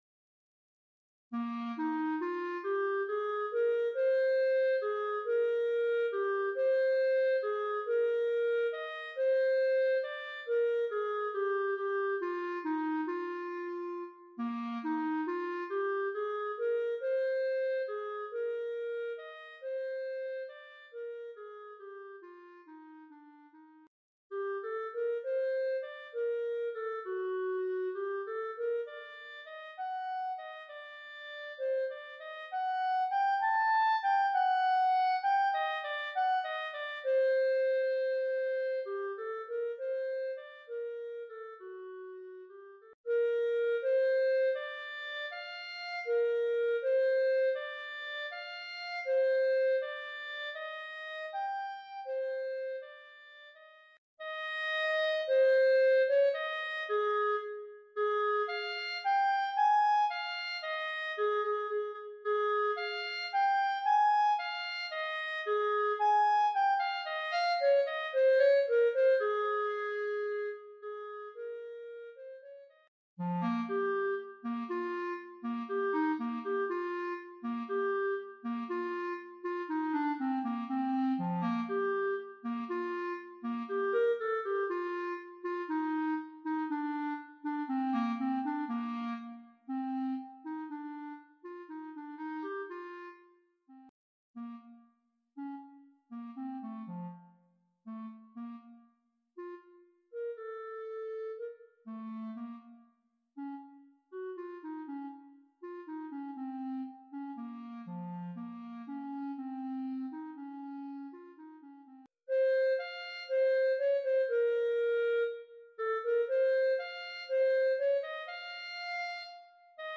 Collection : Clarinette sib